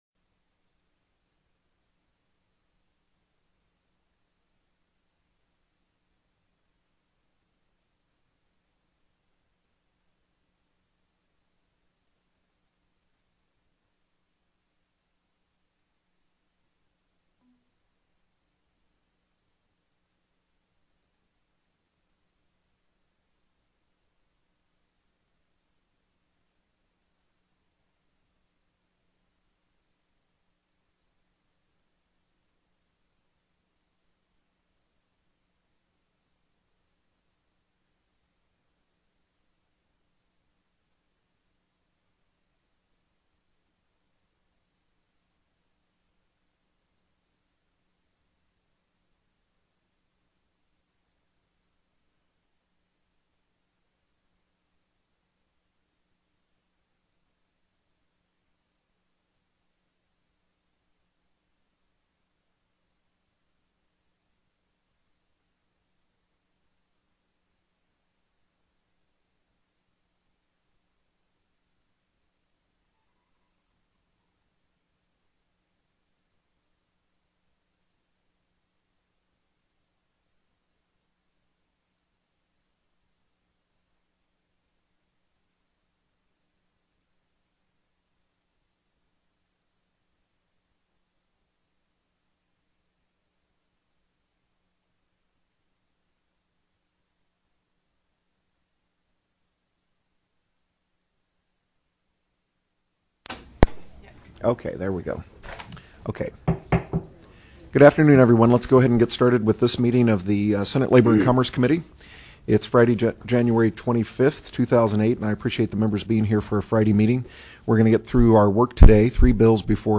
01/25/2008 03:00 PM Senate LABOR & COMMERCE